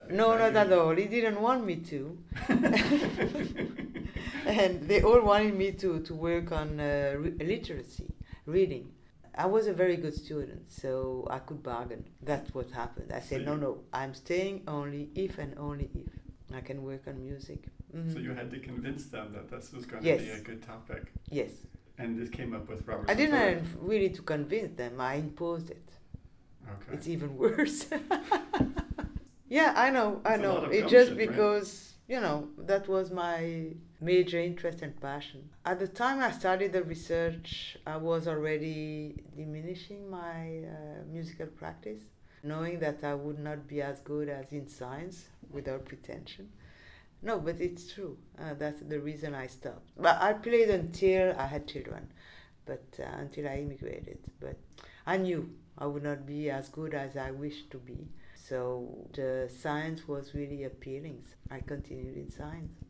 Here, Dr. Peretz describes the reception to this idea: